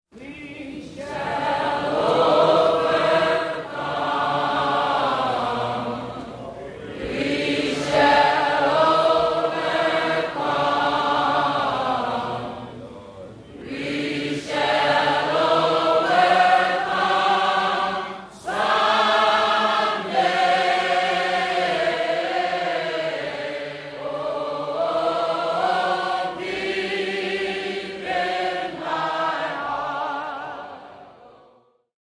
Led by Fannie Lou Hamer, the theme song of the Movement closed a mass meeting in Hattiesburg, Mississippi, in 1964.